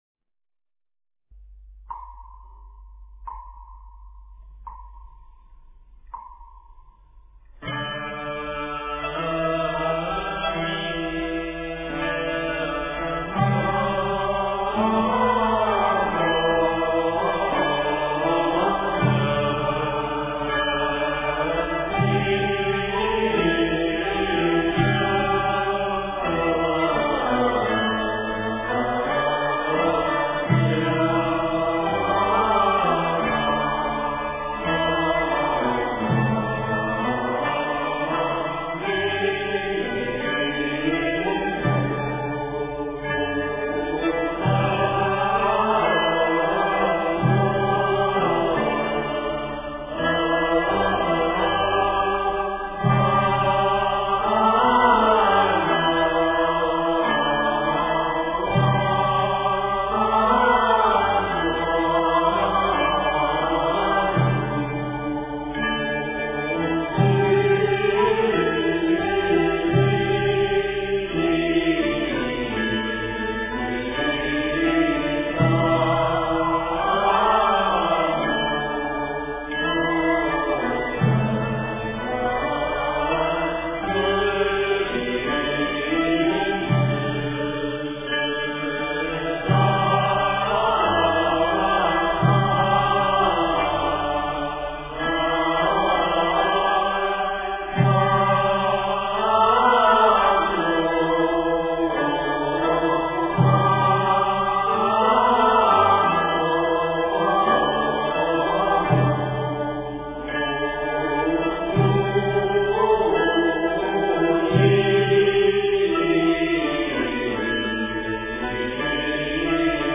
标签: 佛音经忏佛教音乐